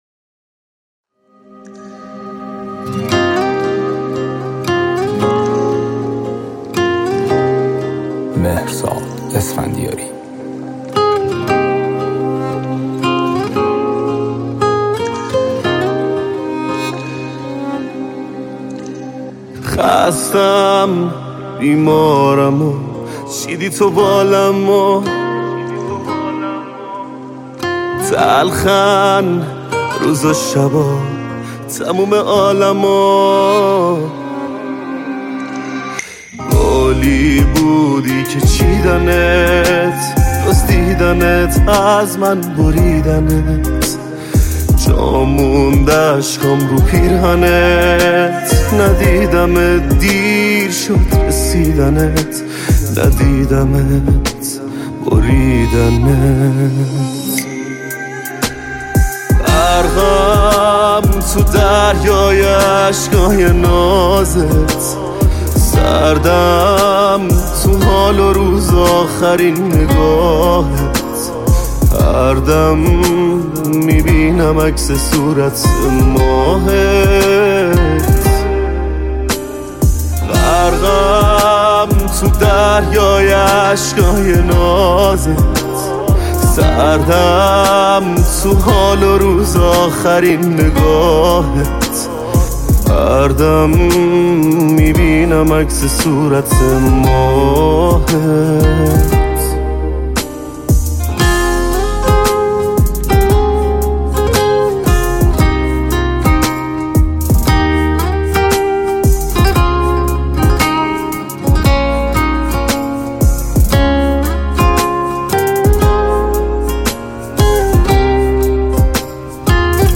آهنگهای پاپ فارسی
کیفیت بالا